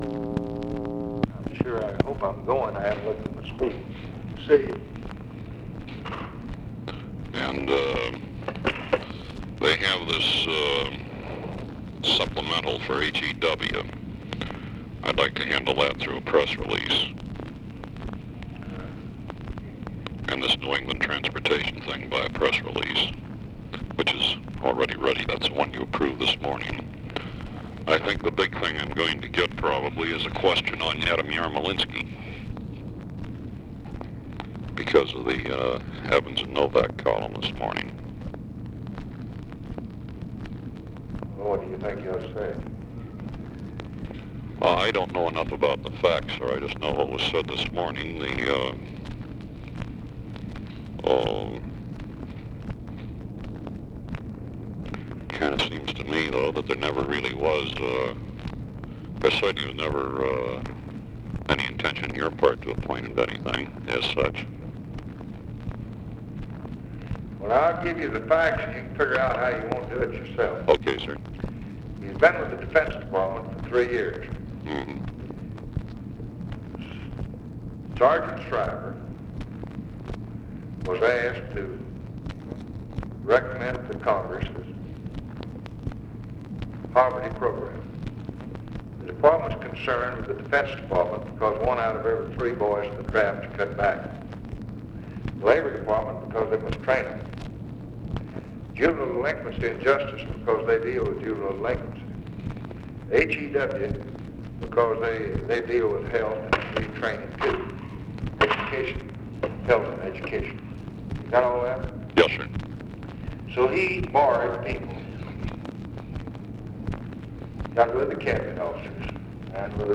Conversation with GEORGE REEDY, August 11, 1964
Secret White House Tapes | Lyndon B. Johnson Presidency Conversation with GEORGE REEDY, August 11, 1964 Rewind 10 seconds Play/Pause Fast-forward 10 seconds 0:00 Download audio Previous Conversation with DAVE GARTNER?